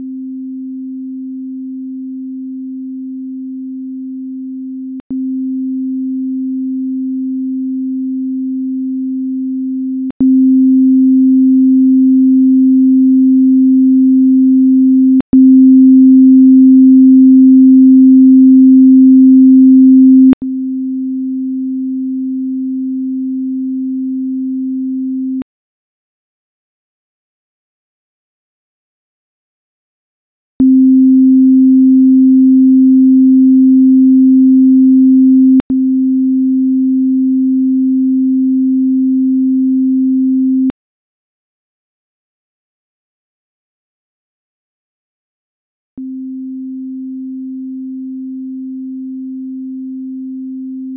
I don't want you to hurt your hearing or damage your speakers trying to solve this one.
Bell.wav